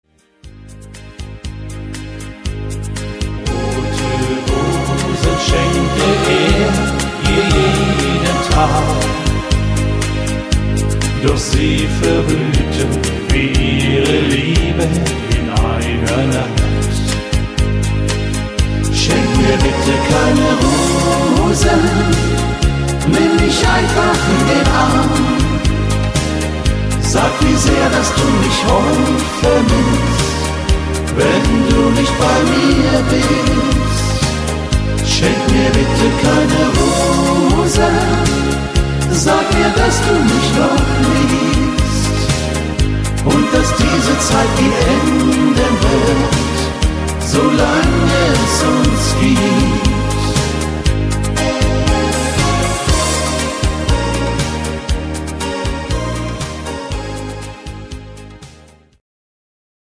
Rhythmus  Rhumba
Art  Deutsch, Volkstümlicher Schlager